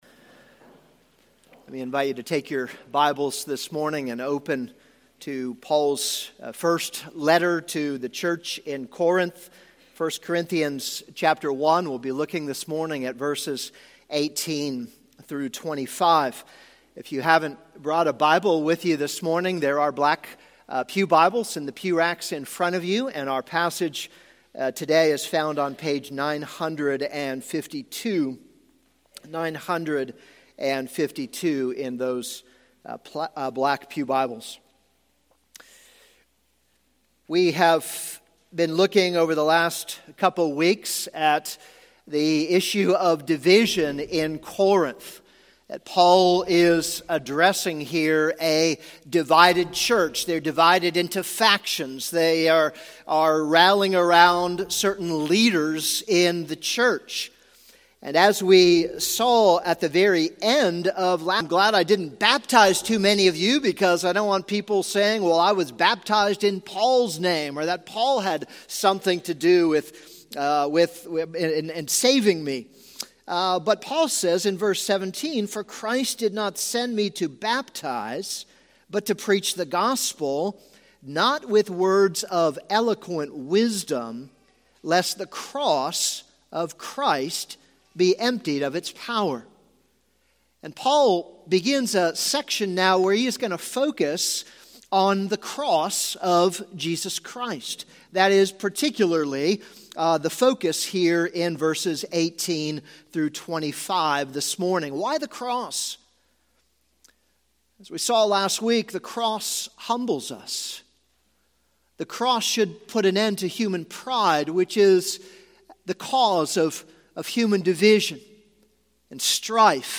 This is a sermon on 1 Corinthians 1:18-25.